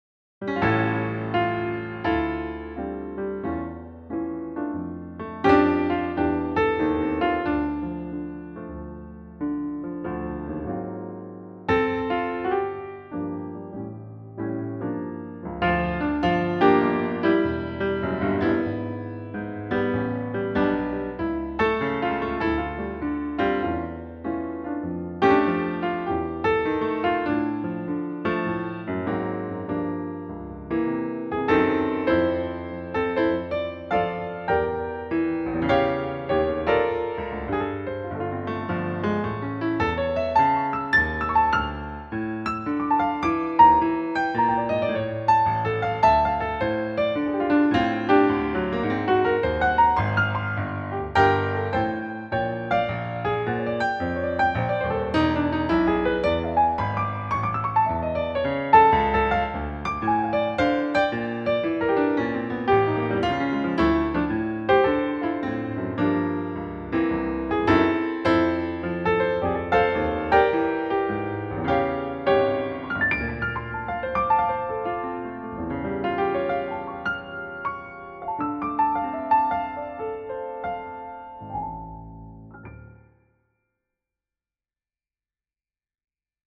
Dinnerpianist, Middagspianist, Hyggepianist, Baggrundsmusik, Klaver, Pianist, Solopianist, Solo, Jaz
summertime-piano-demo-ab.mp3